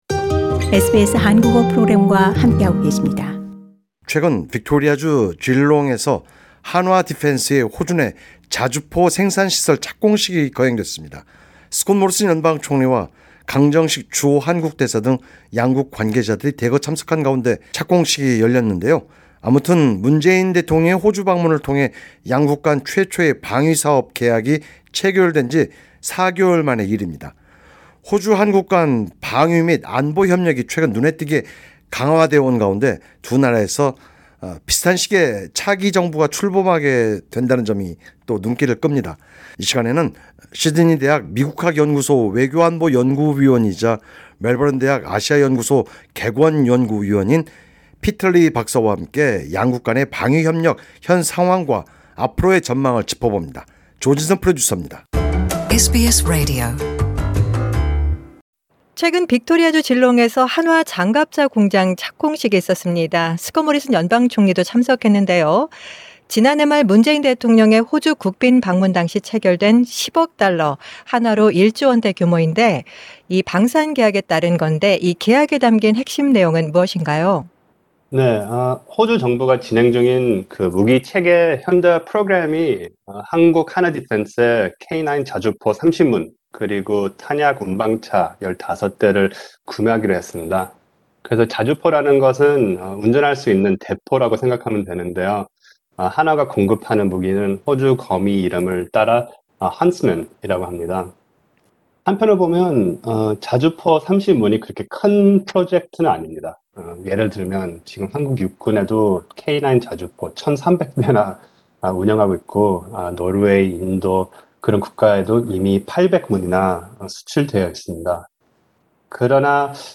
박사와 양국 간 방위 협력의 현 상황과 앞으로의 전망을 짚어본 인터뷰 전문이다.